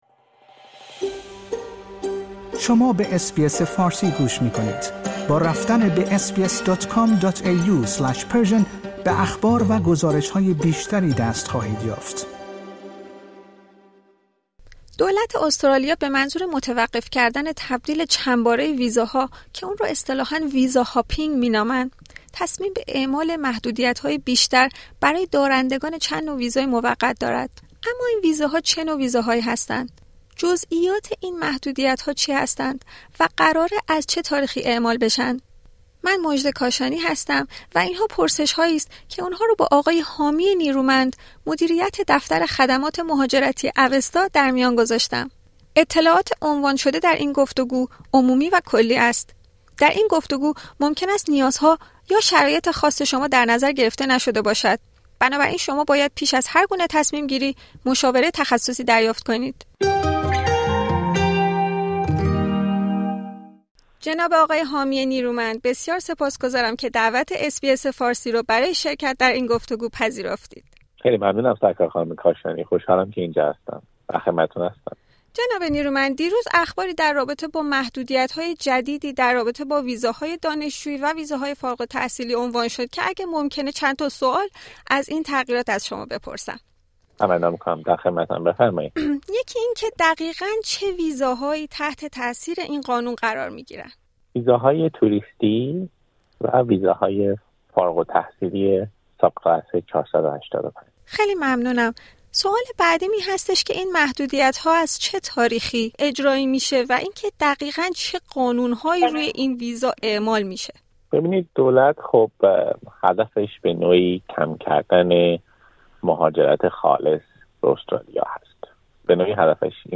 SUPPLIED اطلاعات عنوان شده در این گفتگو، عمومی و کلی است.